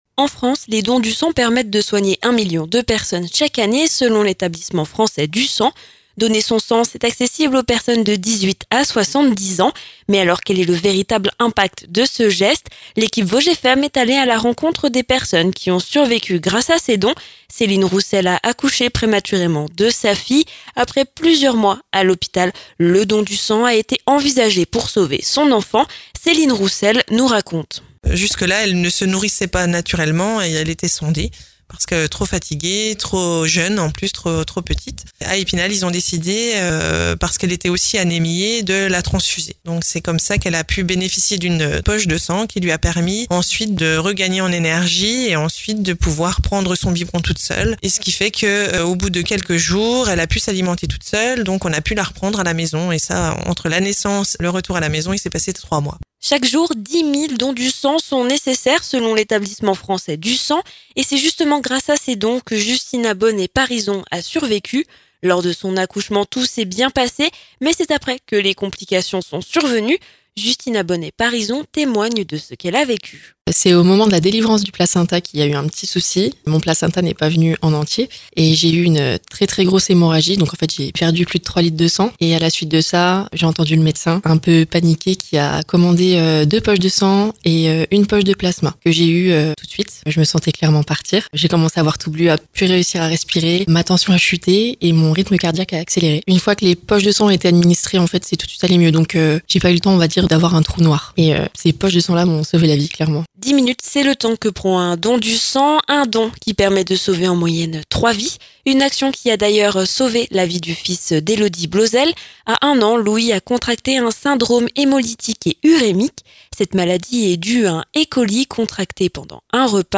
%%La rédaction de Vosges FM vous propose l'ensemble de ces reportages dans les Vosges%%
Donner son sang ne prend que dix minutes, mais cela peut sauver jusqu’à trois vies. L’équipe de Vosges FM est allée à la rencontre des Vosgiens qui ont survécu grâce à ces dons.